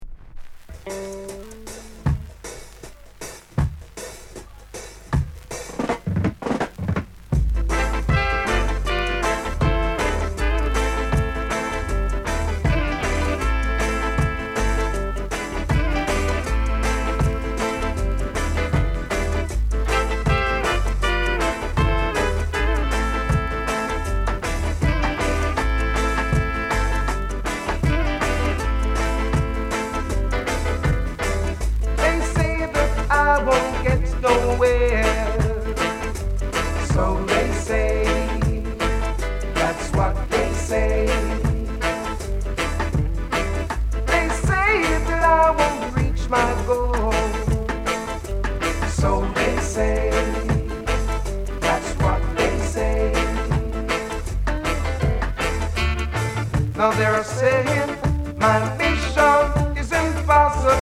slight warp